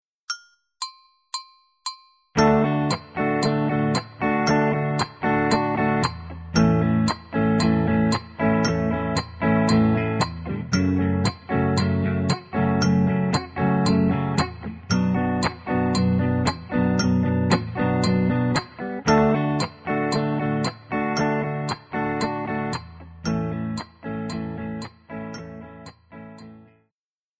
W nagranych przykładach będą pojawiać się, omówione wcześniej, martwe nuty na 2, 4:
D                    A                      F#m               Bm
Tym razem akordy będą się zmieniały co dwa takty:
* Na 4, w co drugim takcie, jest pauza ćwierćnutowa.